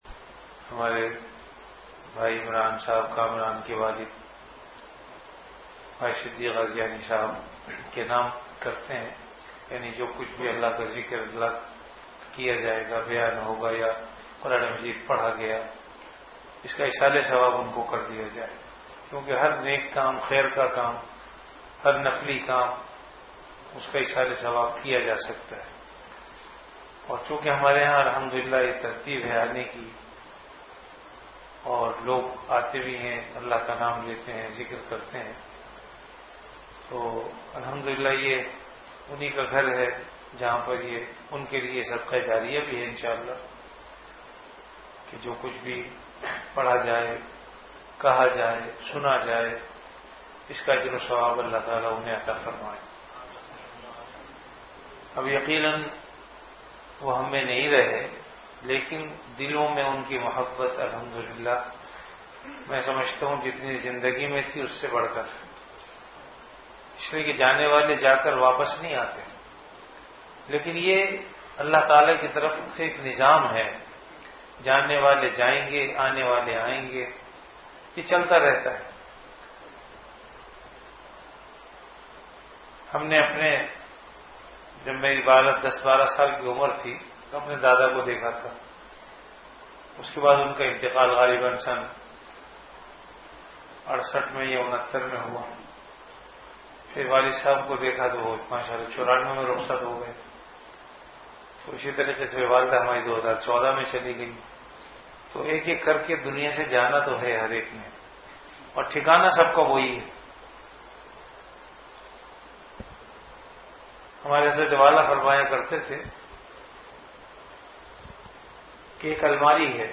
Delivered at Home.
After Isha Prayer